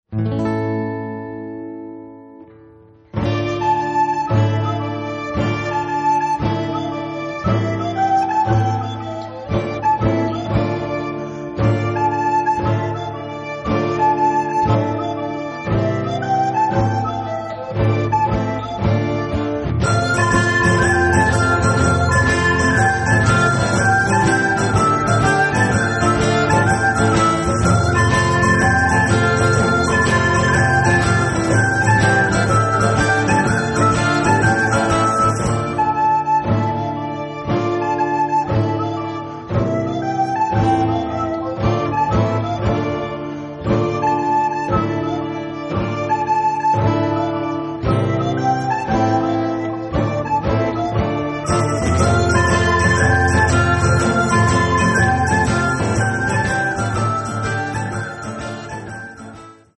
Рок
все инструменты, вокал